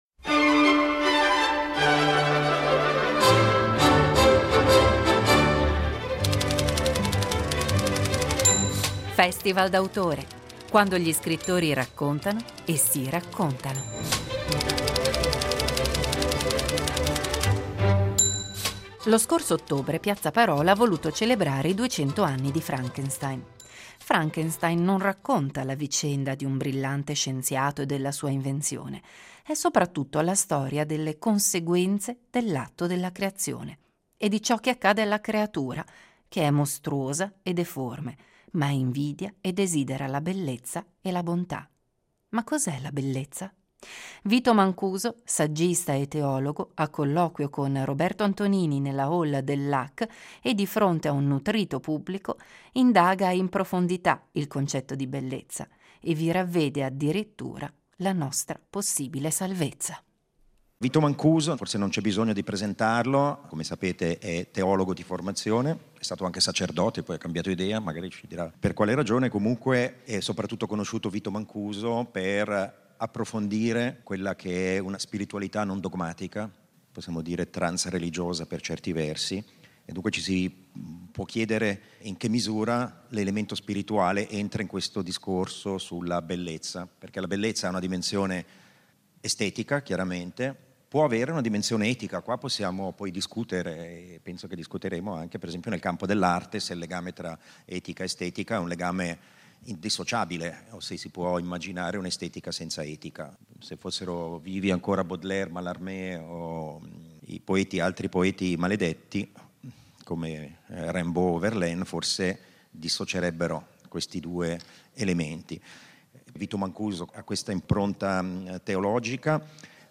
nella hall del LAC e di fronte a un nutrito pubblico